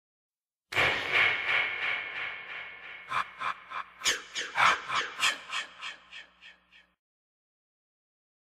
Jason Voorhees Horror Sound Effect Free Download
Jason Voorhees Horror